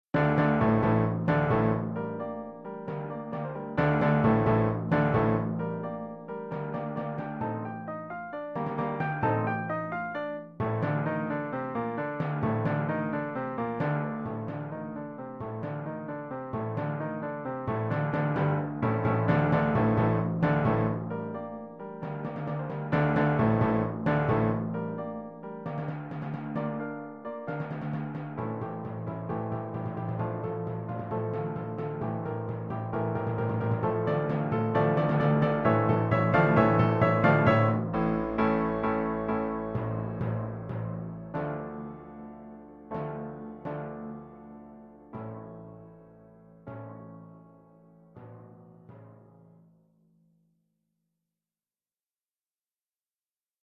Oeuvre pour timbales et piano.